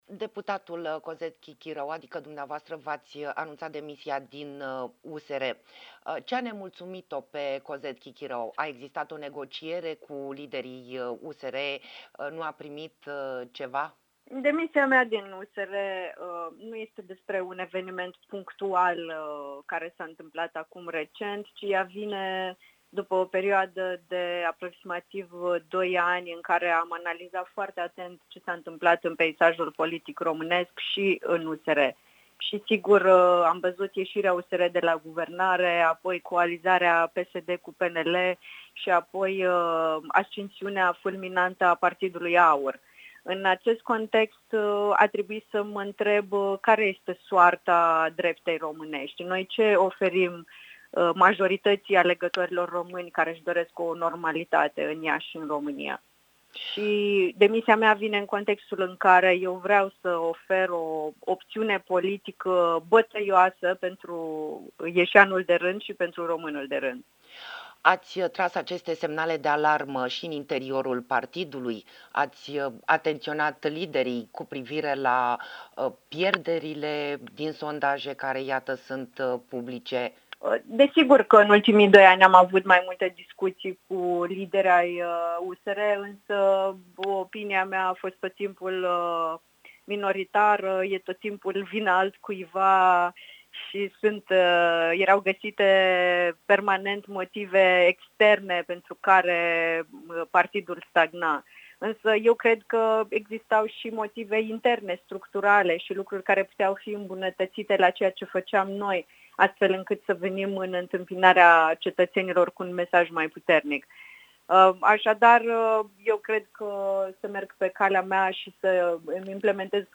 Interviu-demisie-USR.mp3